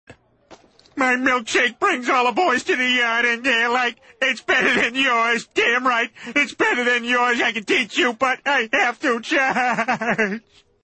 • Voice Tones Ringtones